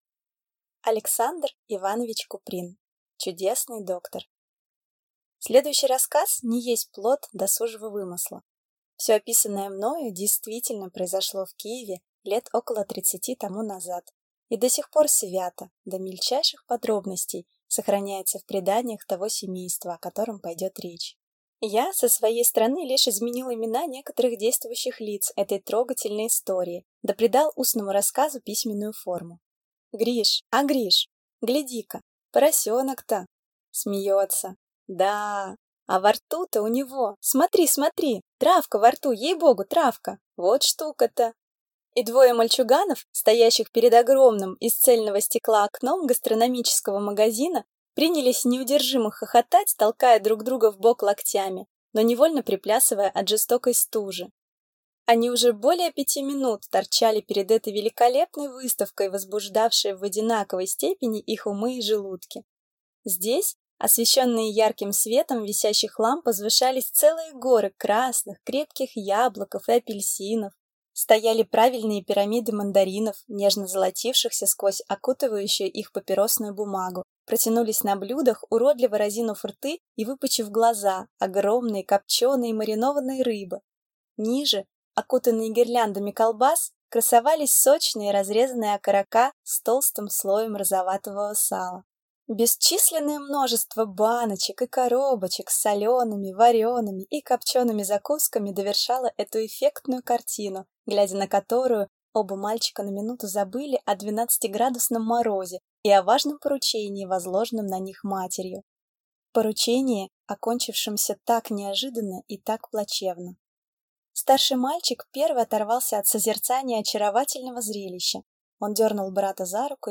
Аудиокнига Чудесный доктор | Библиотека аудиокниг